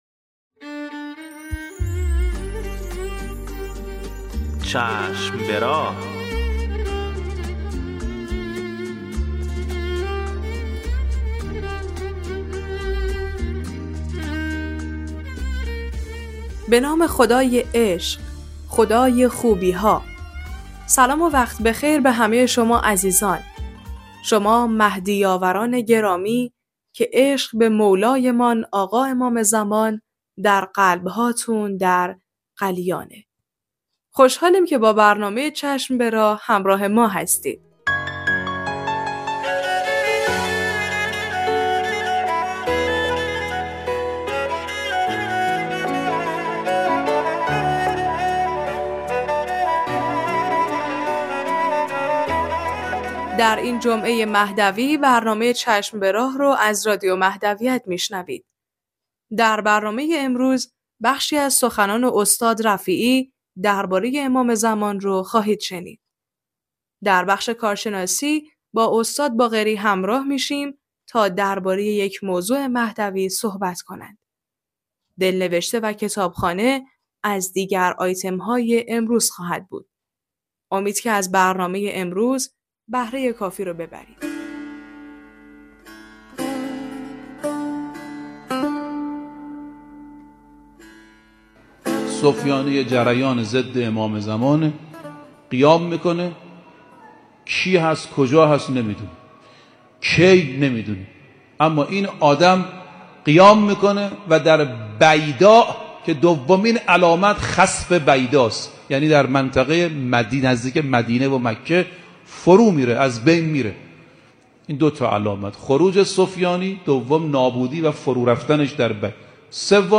قسمت صد و پنجاه و پنجم مجله رادیویی چشم به راه که با همت روابط عمومی بنیاد فرهنگی حضرت مهدی موعود(عج) تهیه و تولید شده است، منتشر شد.